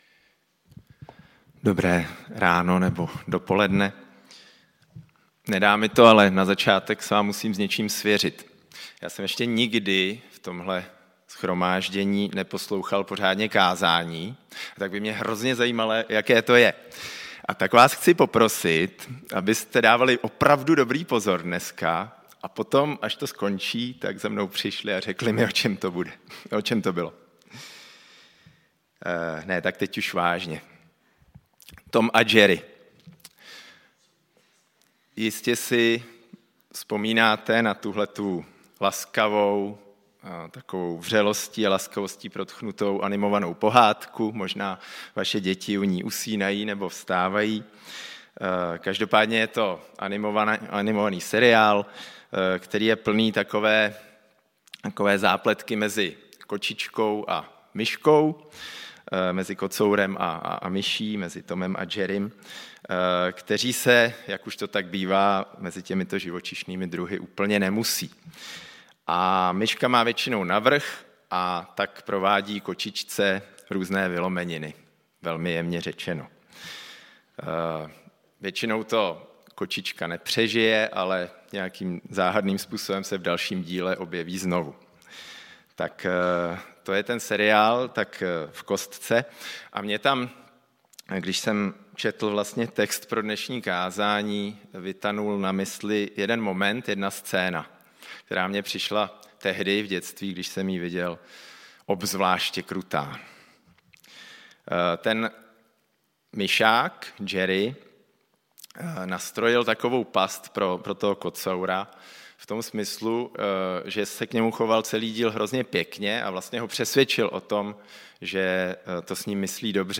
Nedělní bohoslužby přehrát